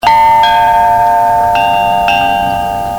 Each bell produces three tuned notes: the larger the windbell the deeper the pitches and richer the tones.
The 2 dot windbell (11.5") has three voices ranging from mid to high pitch. It's tone is soft and clear.